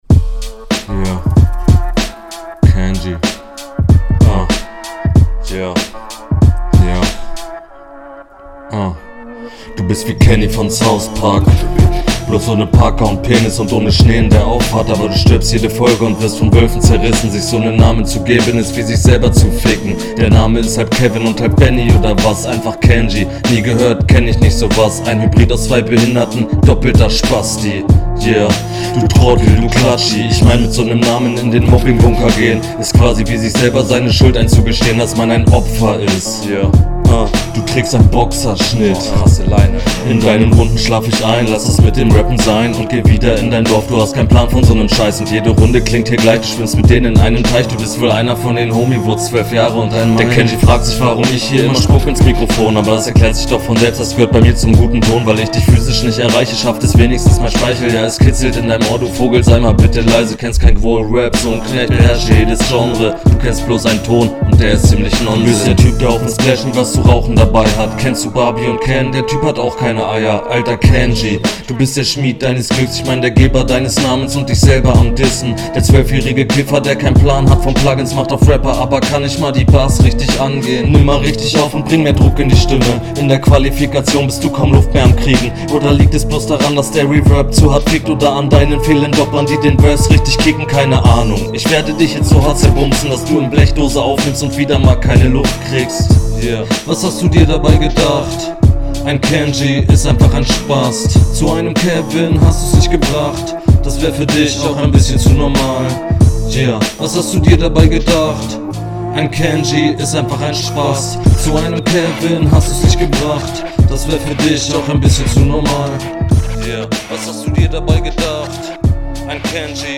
Schon wesentlich besser einige lustige lines dabei und Flow auch sehr souverän durchgezogen ich finde …
emm ja, wo sollen wir anfangen, stabiler aber monotoner flow und sehr druckloser stimmeinsatz, viele …